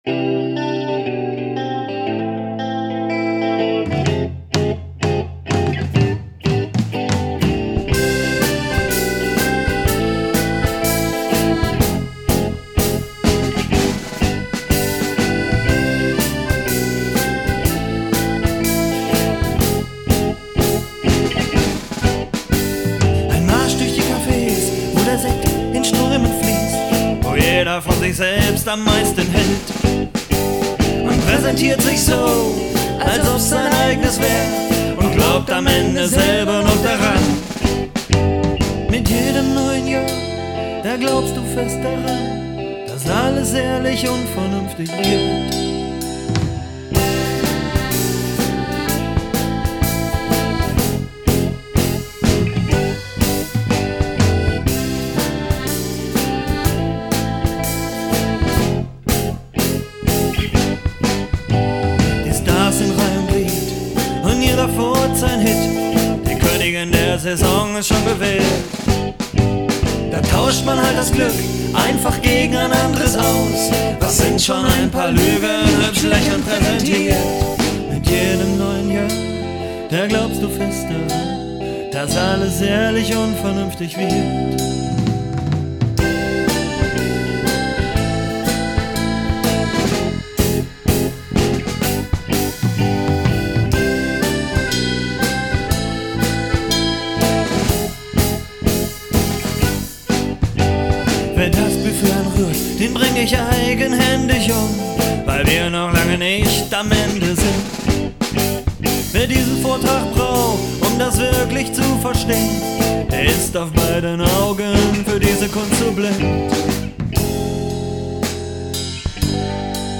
Unsere Demo-CD´s sind alle in Eigenregie entstanden.
Gitarre Gesang Klavier
Schlagzeug Percussion
Bass